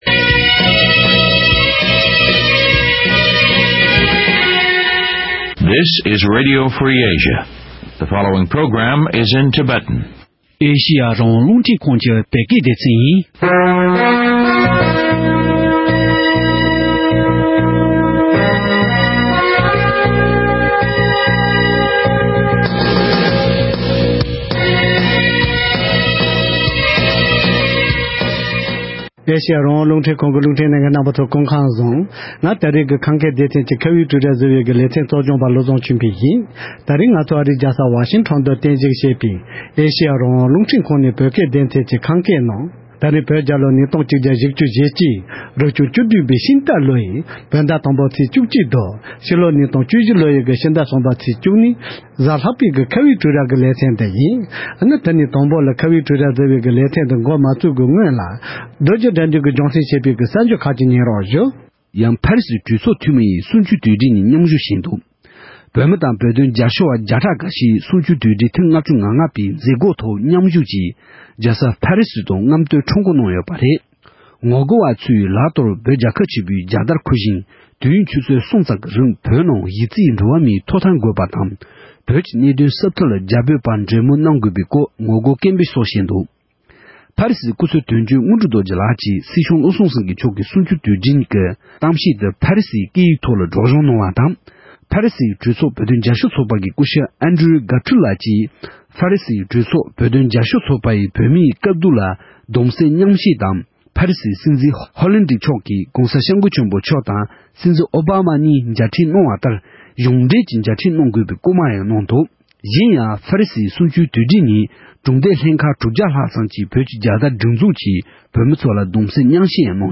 བོད་མིའི་ཞི་བའི་སྒེར་ལངས་བྱས་ནས་ལོ་ངོ་༥༥འཁོར་བ་དང་བསྟུན། གསུམ་བཅུའི་དུས་དྲན་གྱི་སྐོར་ལ་འབྲེལ་ཡོད་མི་སྣ་ཁག་ཅིག་དང་ལྷན་དུ་བགྲོ་གླེང་ཞུ་རྒྱུ་ཡིན།